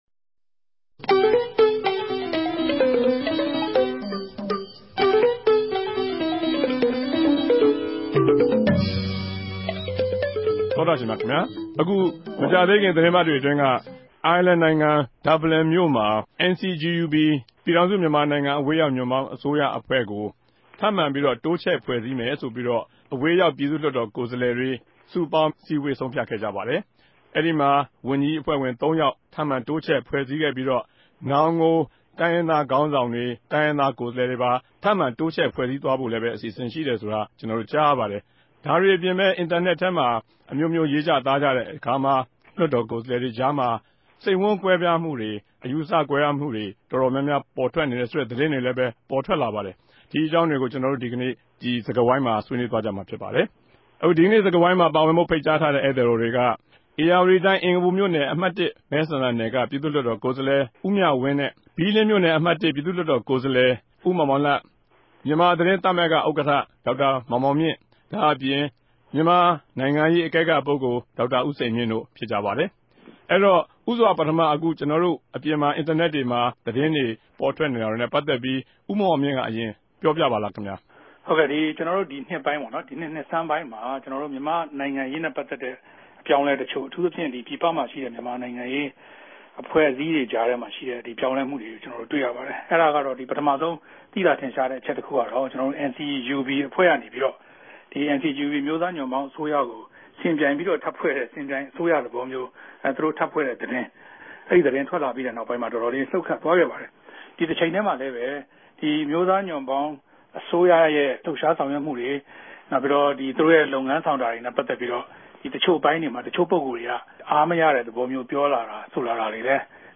တနဂဿေိံစြကားဝိုင်း။